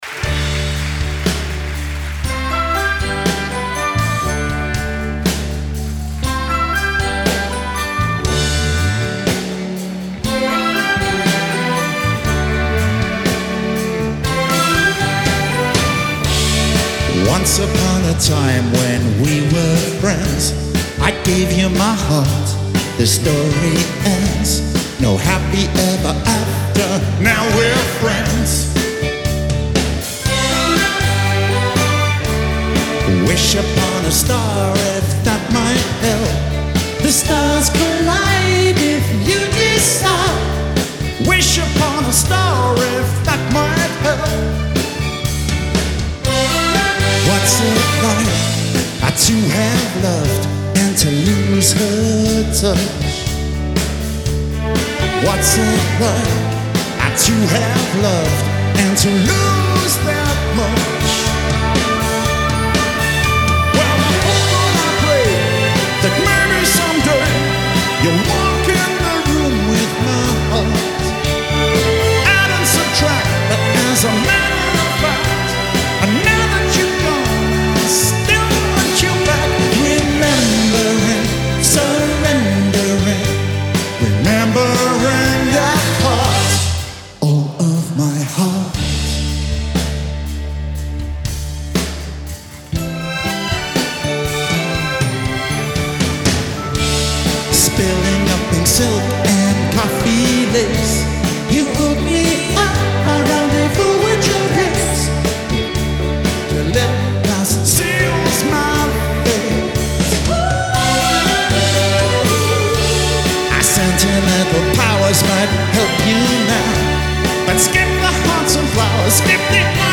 Hi-Res Stereo
Genre : Pop